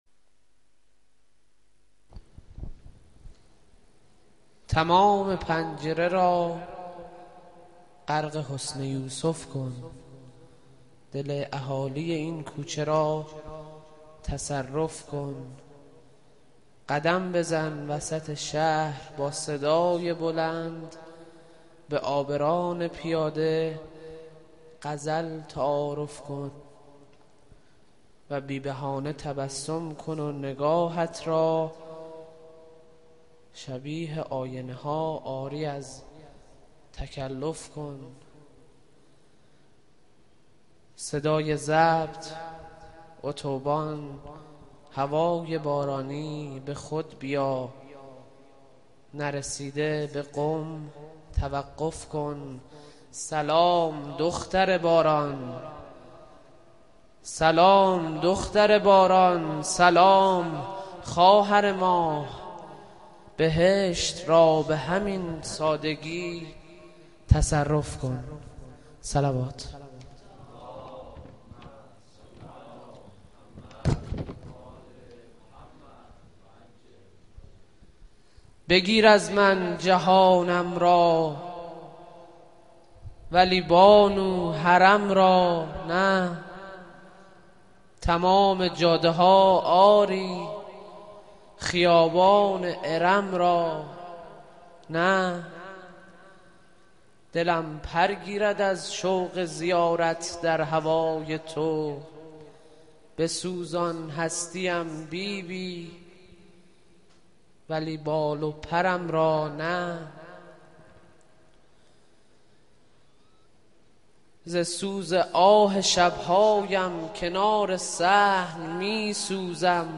شعر خوانی